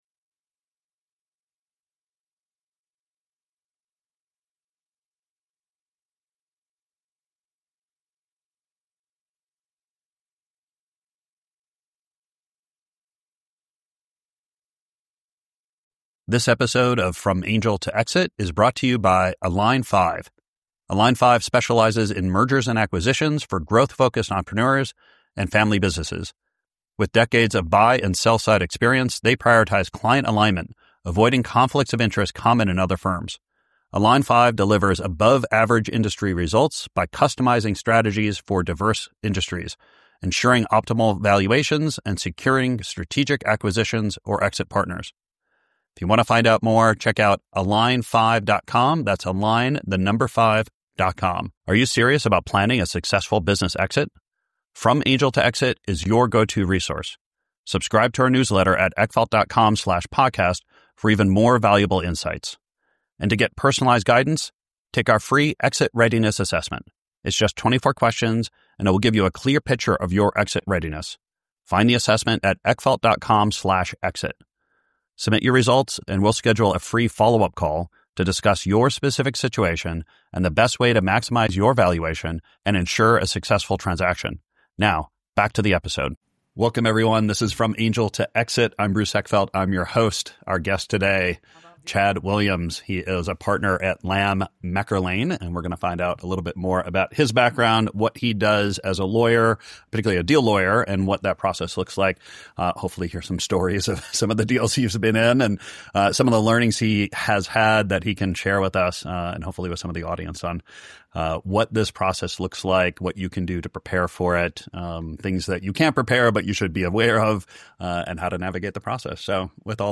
Learn what to do a year before selling, how to protect yourself post-sale, and how to avoid common legal traps that erode value. This conversation is packed with actionable legal insights for founders navigating the complex world of business exits.